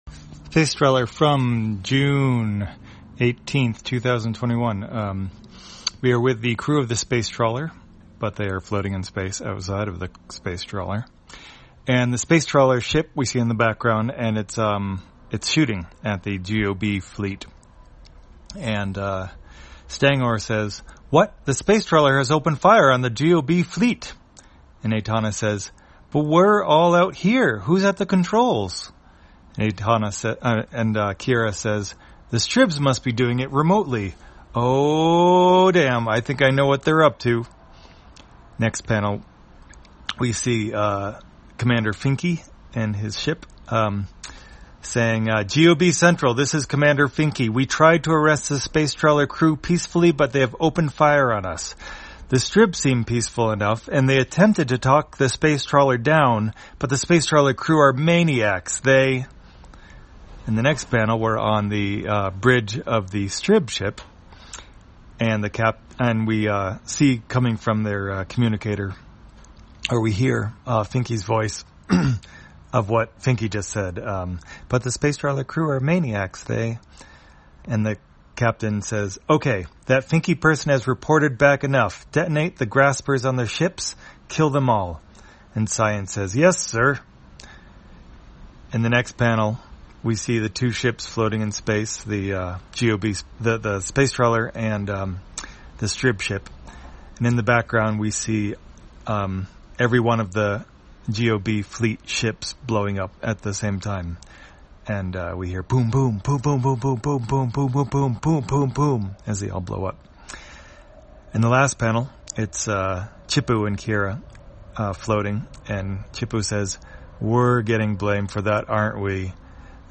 Spacetrawler, audio version For the blind or visually impaired, June 18, 2021.